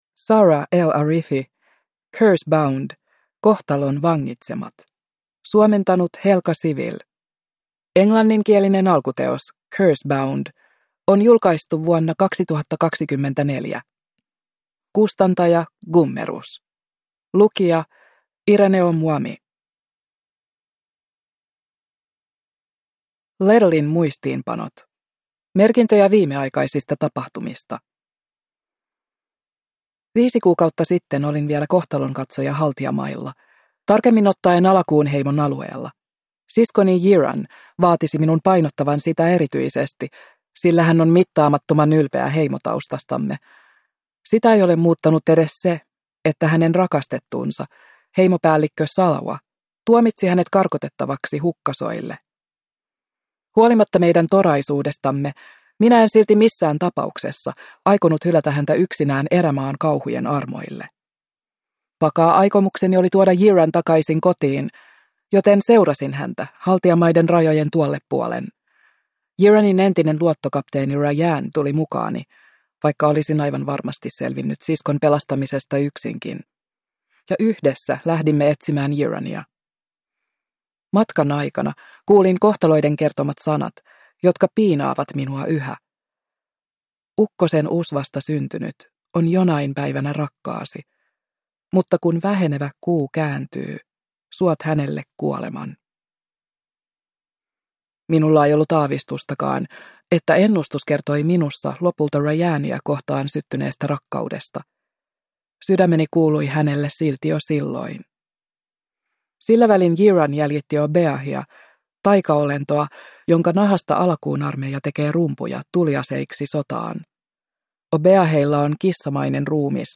Cursebound - Kohtalon vangitsemat – Ljudbok